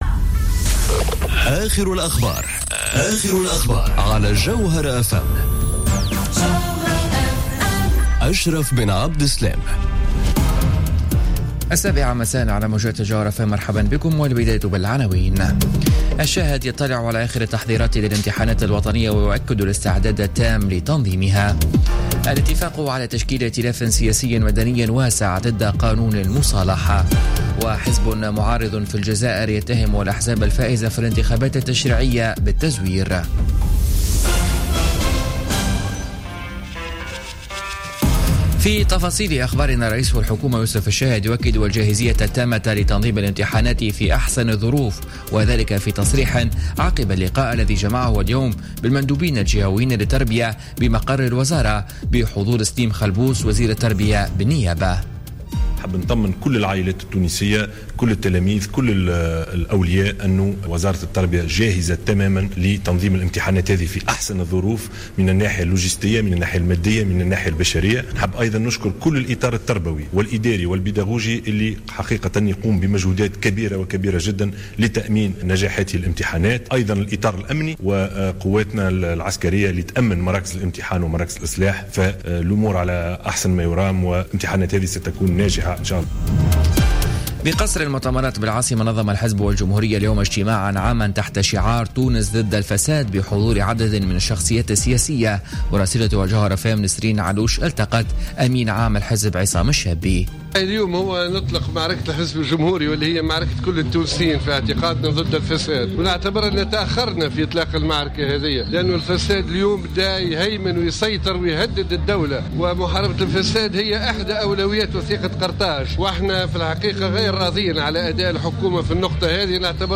نشرة أخبار السابعة مساء ليوم السبت 6 ماي 2017